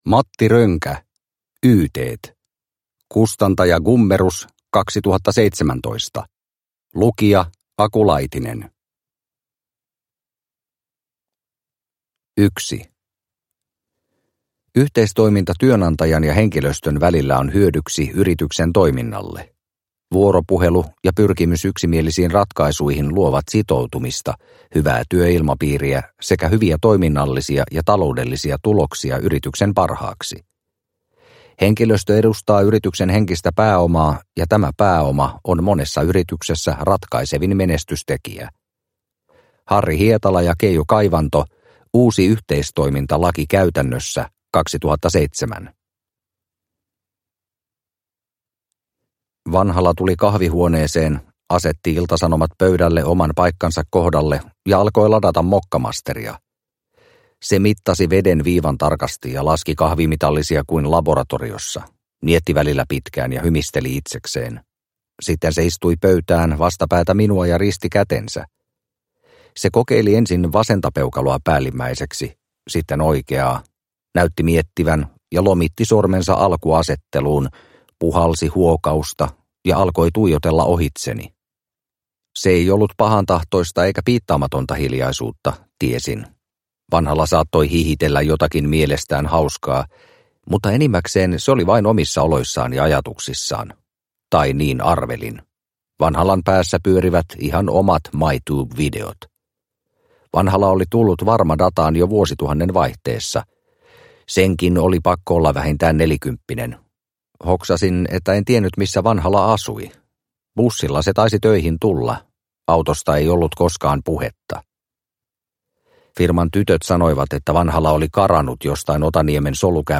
Yyteet – Ljudbok